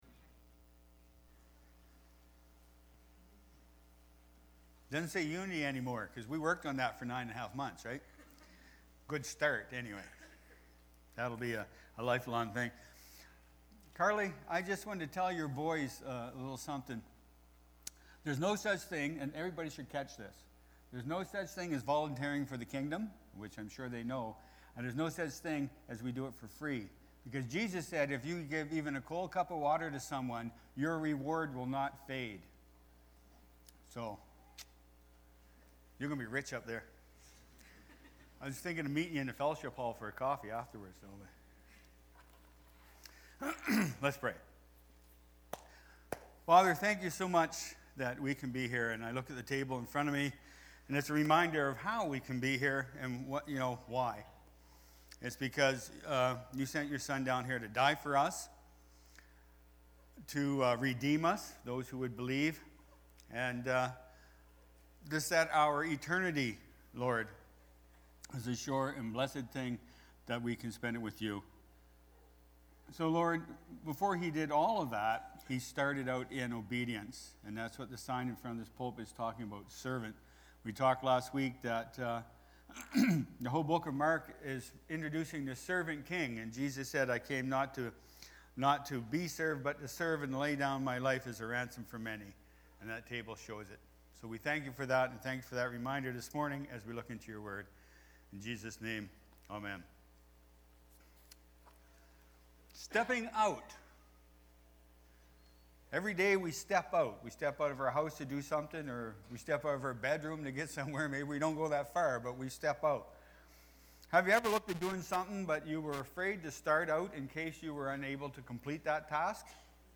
October-1-2023-sermon-audio.mp3